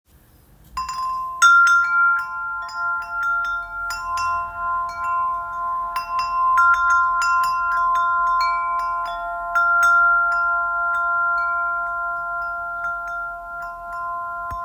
One holds court in a corner of our kitchen, where it catches the tradewind breezes from the dining nook windows.
The other holds court in our living room, where it comes to life in the tradewinds wafting through the windows overlooking our yard.
Chimes1.m4a